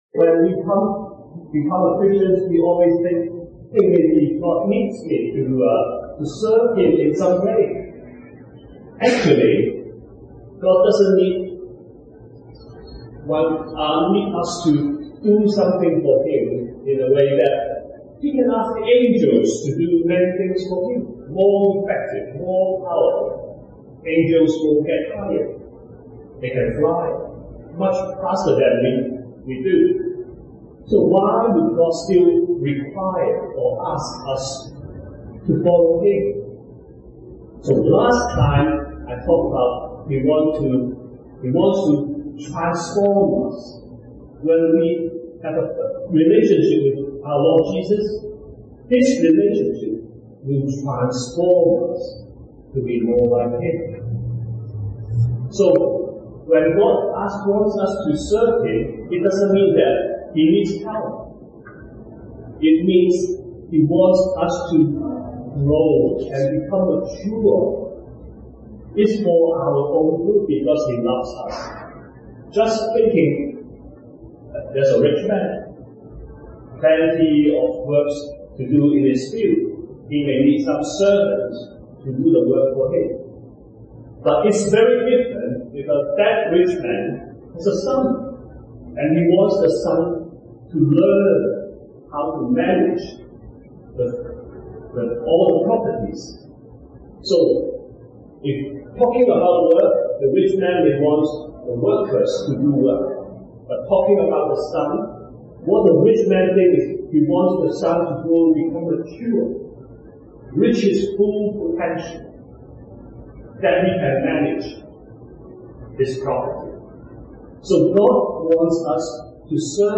Scripture references: Matthew 4:18-20, John 3:16, 2 Peter 2:9, Acts 1:8, John 4:39-41, John 5:15, 9:25, 1:40-42, Luke 5:29, Acts 8:26-30 A brief study to understand why and how to be fishers of men. Links: Slides (as PDF) Audio (Apologies – the audio is unfortunately not good quality)